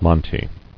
[mon·te]